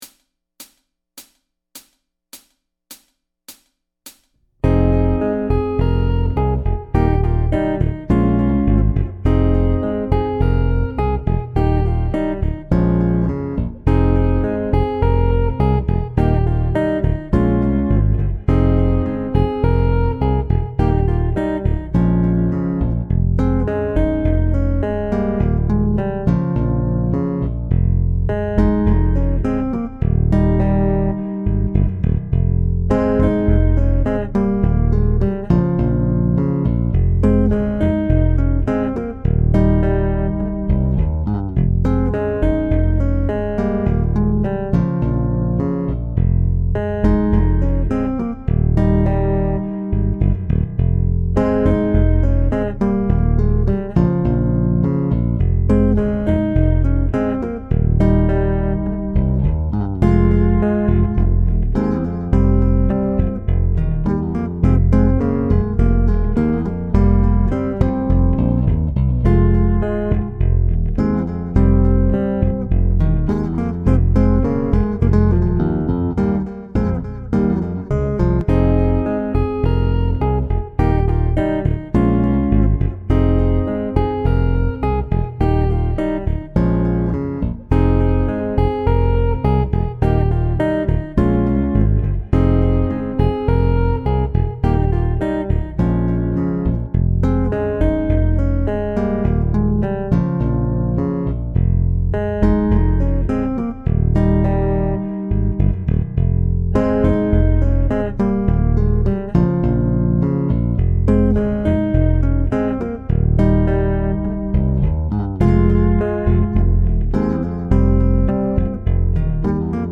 • Instrumental + Voice
• Instrumental
Same bass line left hand.